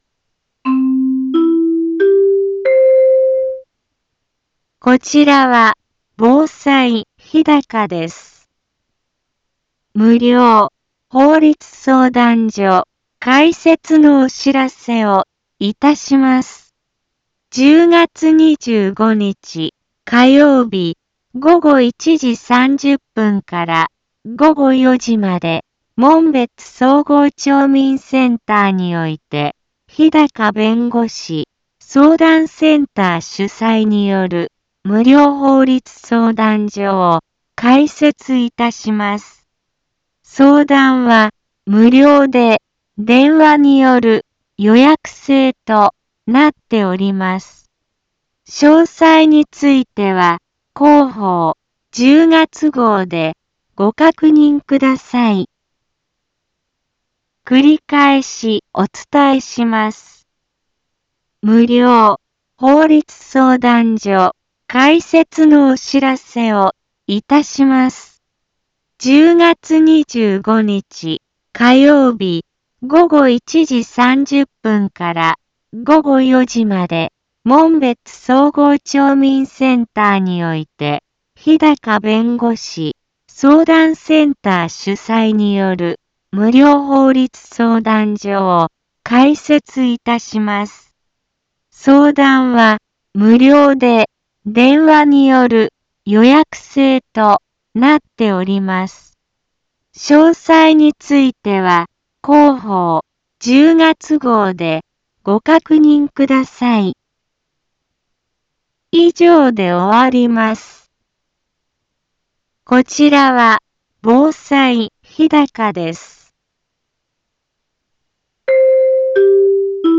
一般放送情報
Back Home 一般放送情報 音声放送 再生 一般放送情報 登録日時：2022-10-20 15:04:16 タイトル：無料法律相談会のお知らせ インフォメーション：こちらは防災日高です。 無料法律相談所開設のお知らせをいたします。